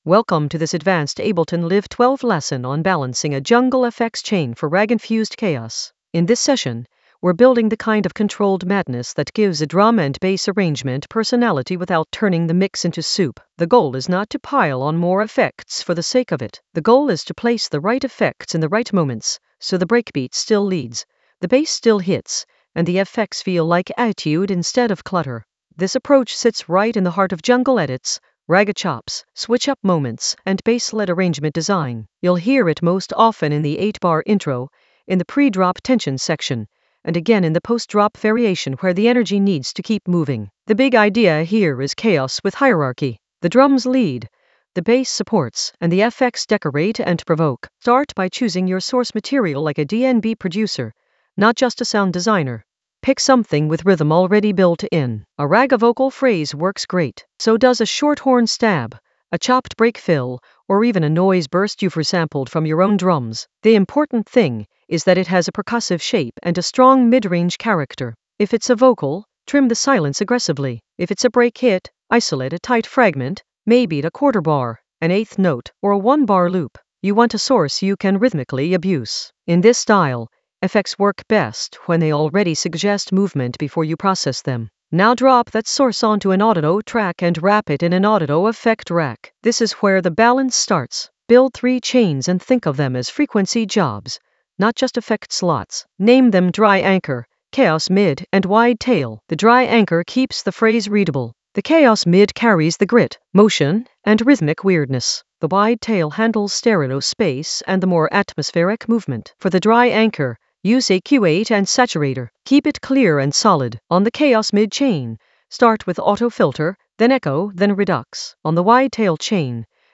Narrated lesson audio
The voice track includes the tutorial plus extra teacher commentary.
An AI-generated advanced Ableton lesson focused on Balance jungle FX chain for ragga-infused chaos in Ableton Live 12 in the Breakbeats area of drum and bass production.